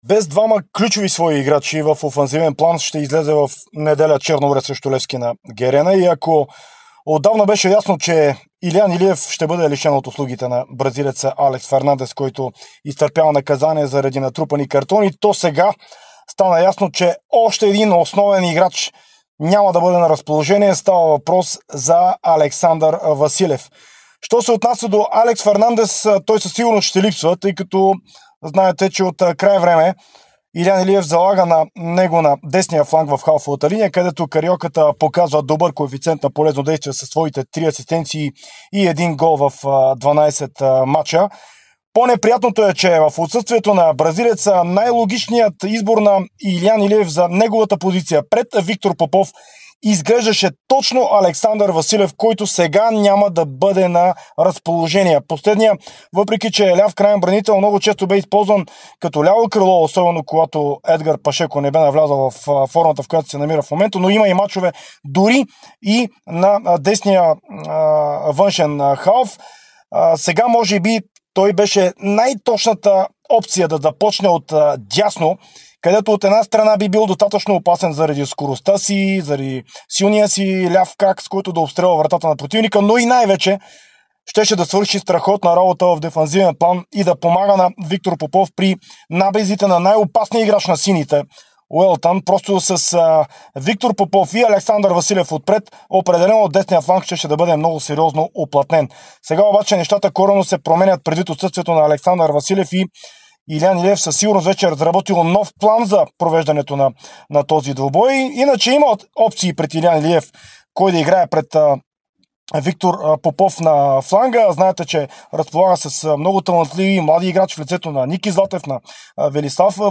Коментар